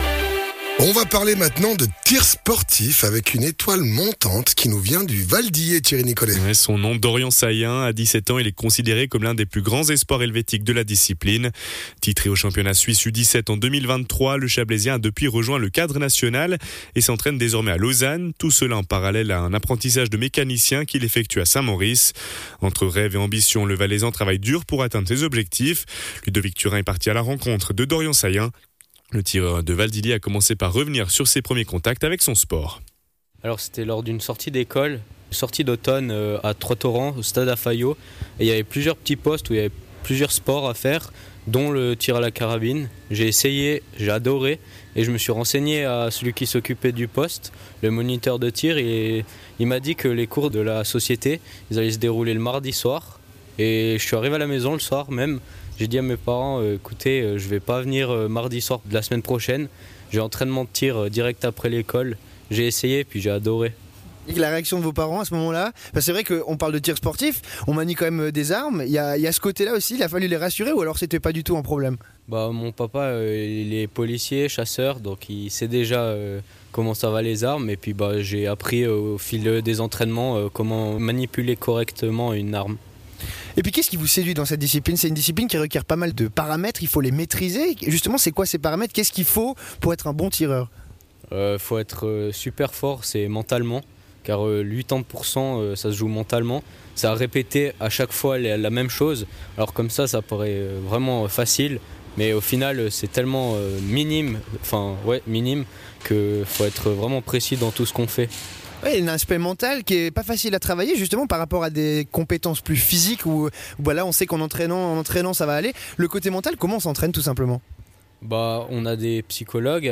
tireur sportif